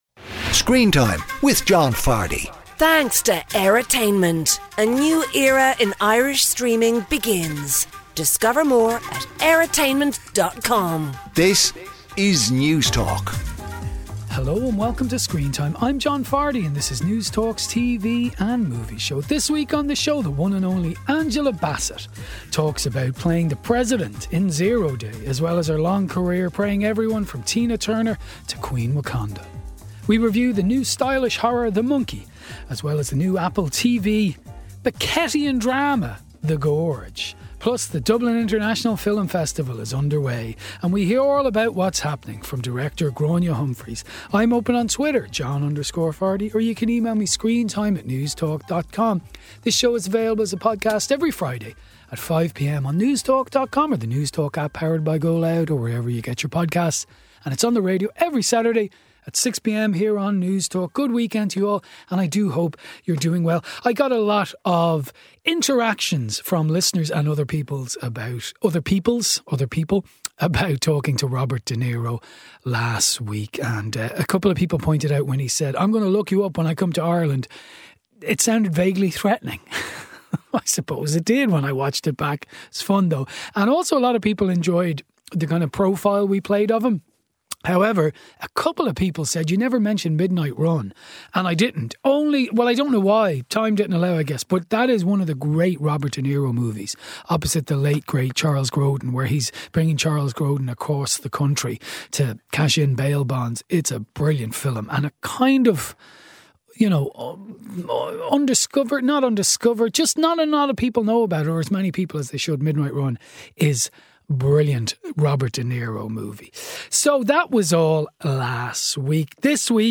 With interviews, reviews and lots of movie and TV treats